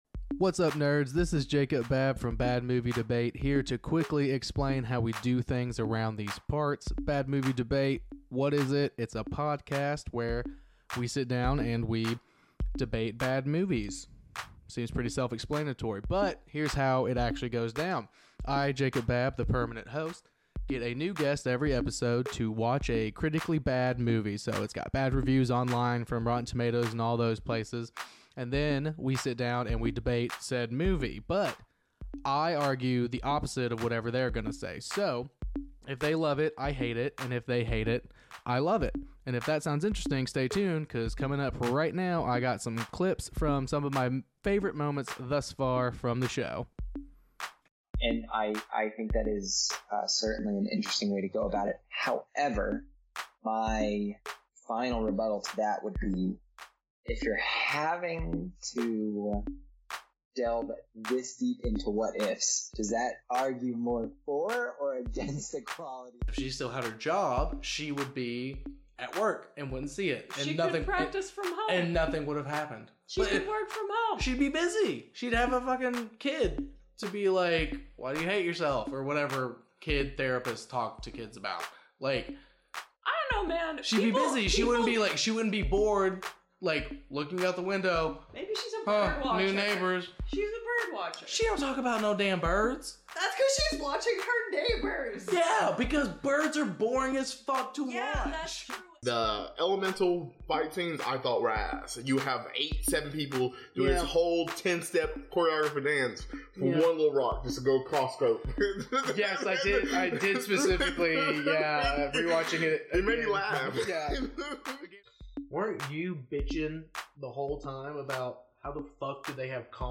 Trailer: